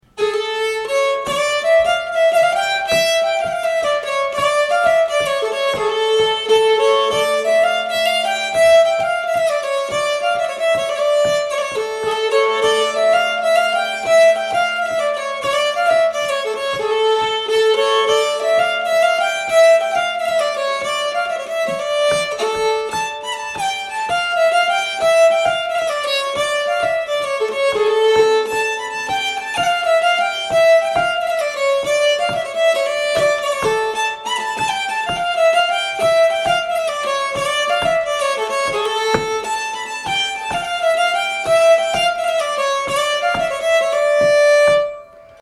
Malungspolska 2